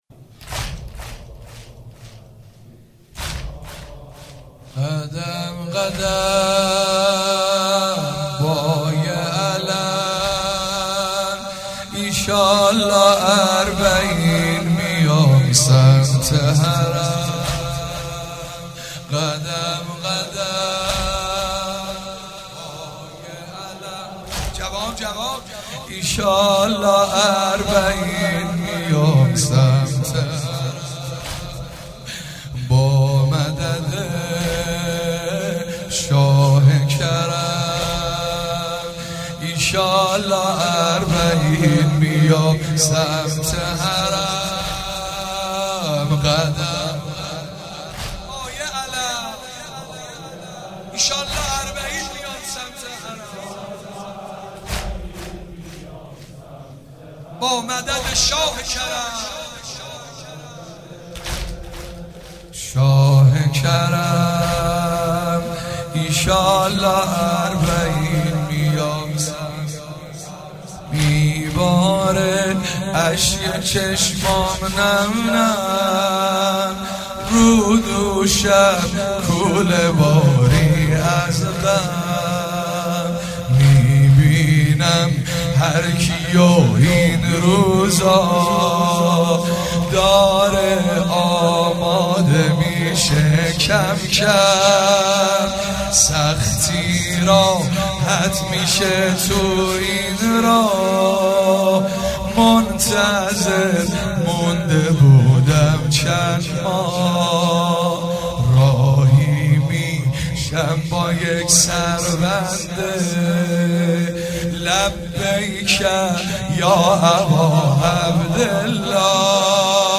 04 marasem 7 safar94 heiate alamdar mashhad alreza.mp3